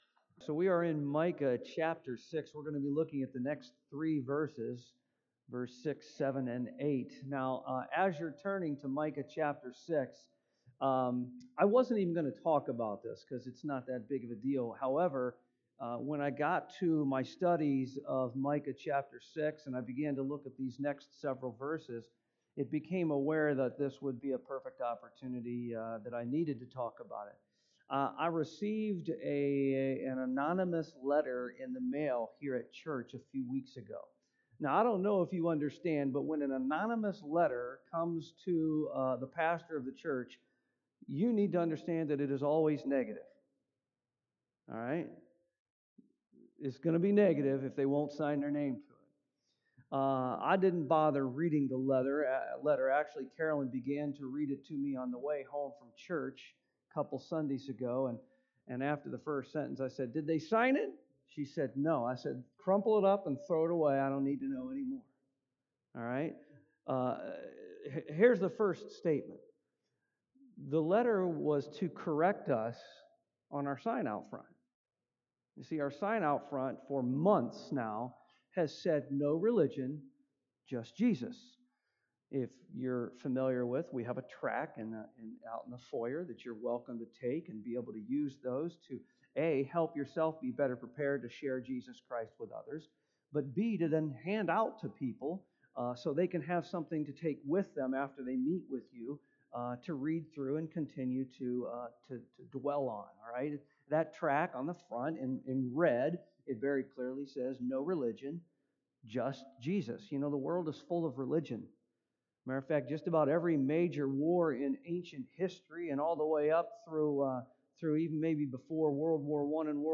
Micah 6:6-8 Service Type: AM Mankind seeks God on his own terms